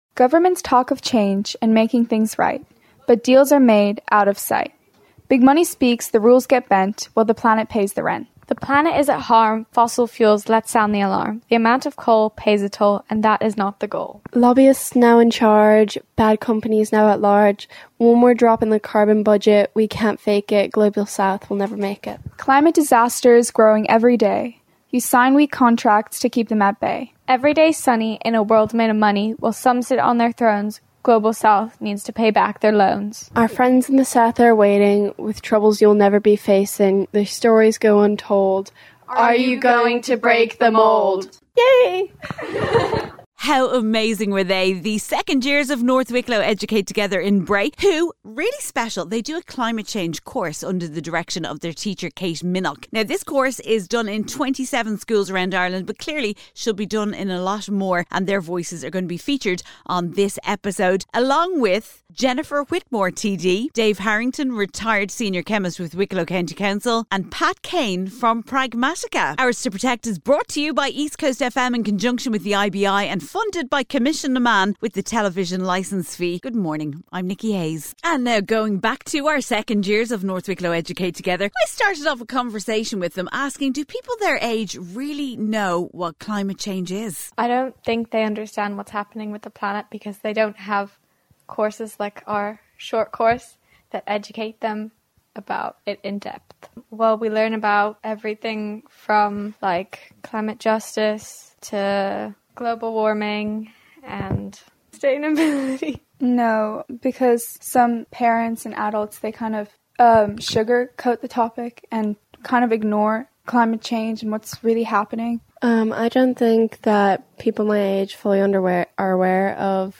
We’ll hear the voices of the children from North Wicklow Educate Together in Bray and they recite a poem that they wrote about climate change in Ireland.